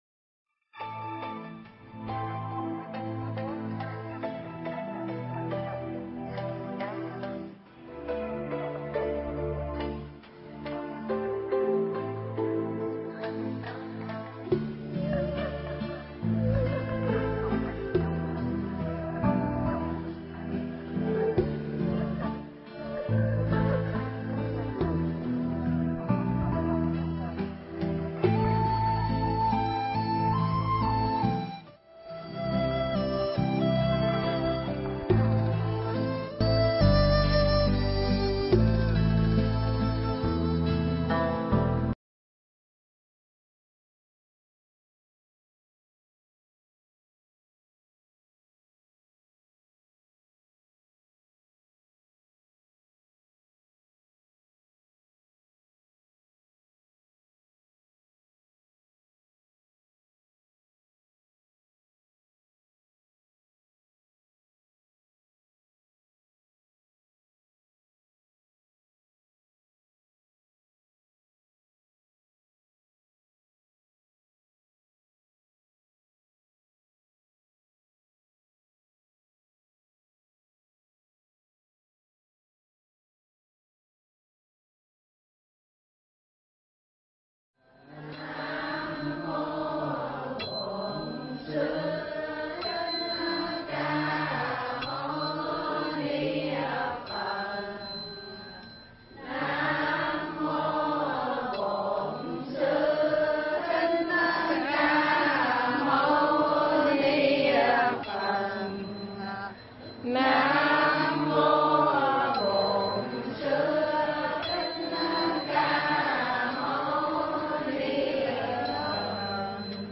Tiểu Thừa Đại Thừa 2 (vấn đáp